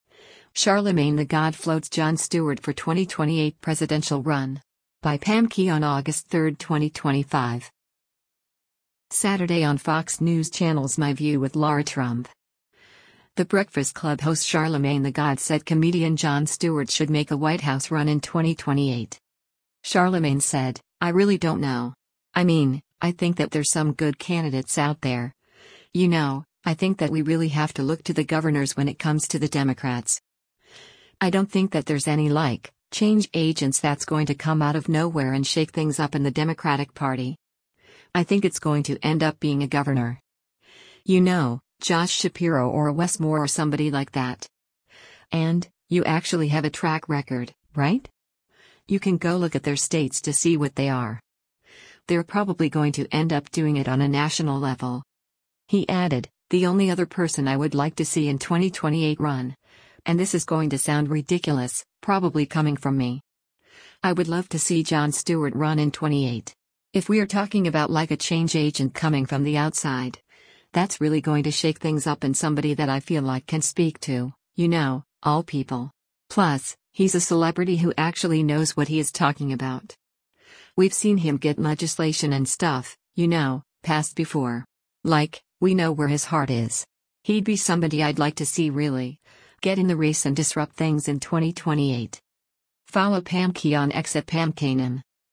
Saturday on Fox News Channel’s “My View with Lara Trump,” “The Breakfast Club” host Charlamagne tha God said comedian Jon Stewart should make a White House run in 2028.